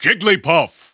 The announcer saying Jigglypuff's name in English releases of Super Smash Bros. Brawl.
Jigglypuff_English_Announcer_SSBB.wav